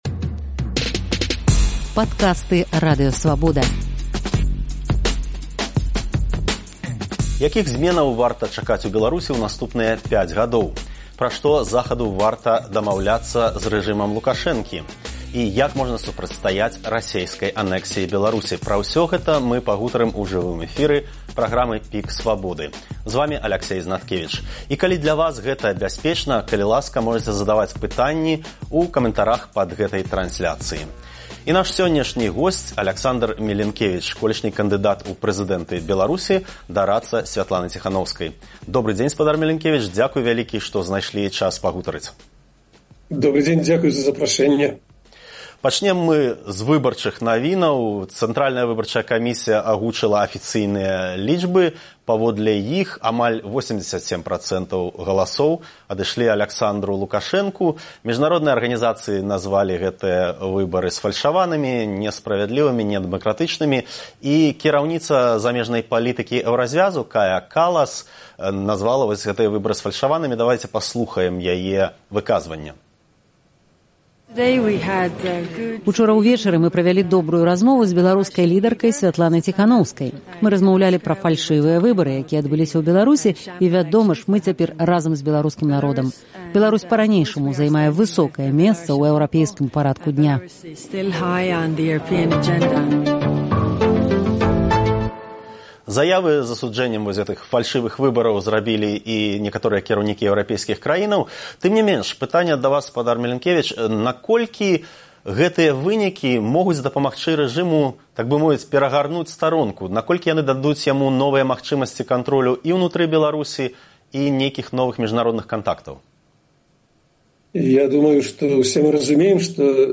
Якіх зьменаў можна чакаць у Беларусі ў наступныя 5 гадоў? Пра што Захаду варта весьці перамовы з рэжымам Лукашэнкі? Як можна супрацьстаяць расейскай анэксіі Беларусі? У праграме «ПіК Свабоды» разважае Аляксандар Мілінкевіч, экс-кандыдат у прэзыдэнты Беларусі, дарадца Сьвятланы Ціханоўскай.